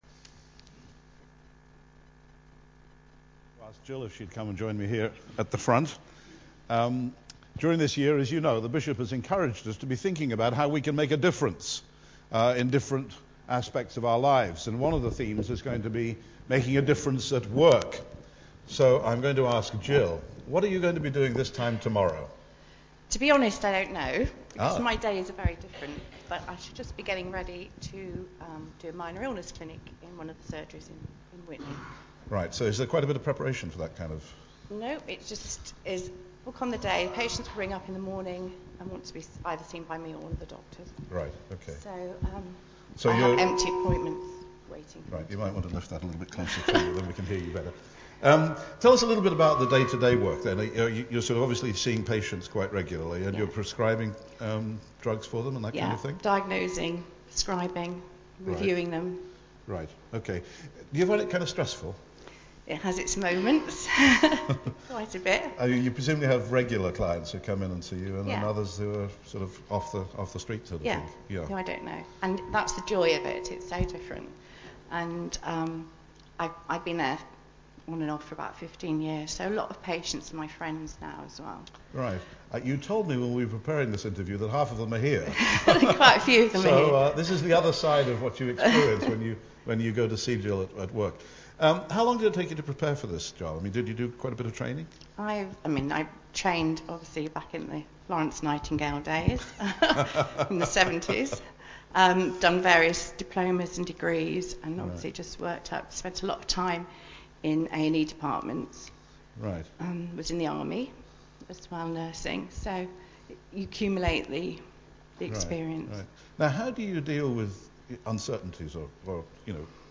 (Apologies for some feedback encountered at the beginning of this recording)